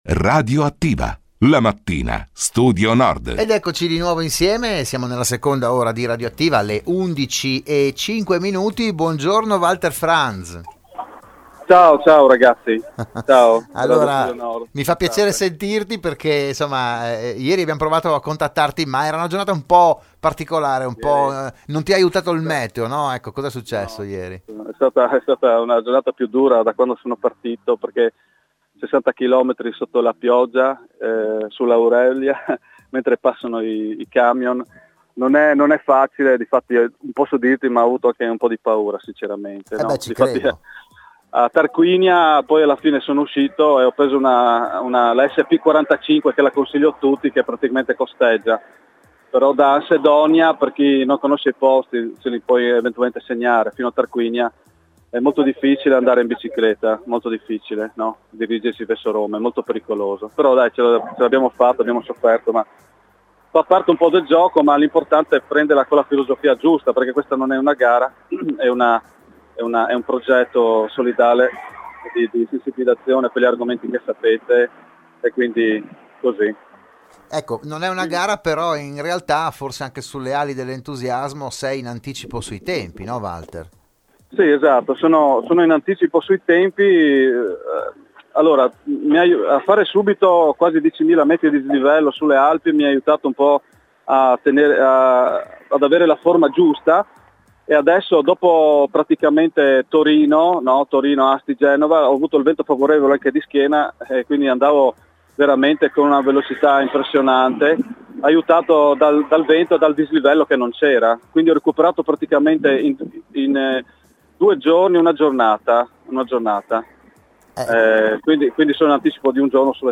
Radioattiva, la trasmissione di Radio Studio Nord
si è nuovamente collegata telefonicamente